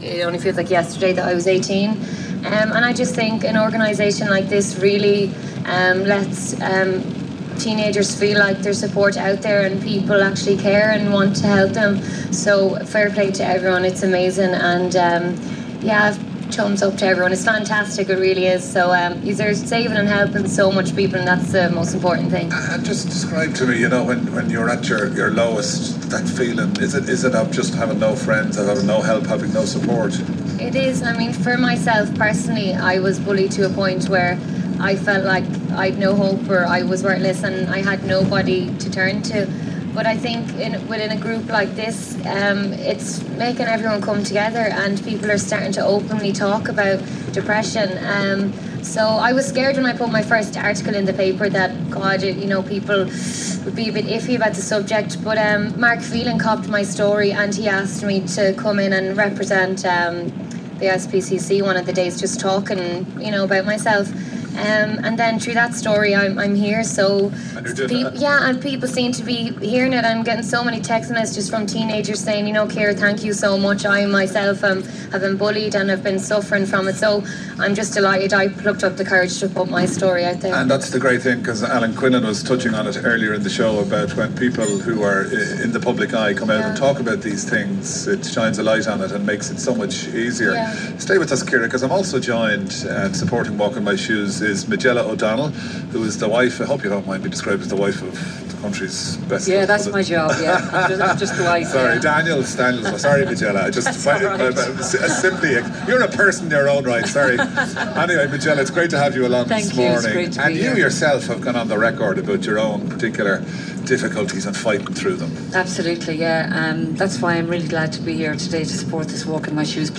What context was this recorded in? Open talk about Depression on RTE radio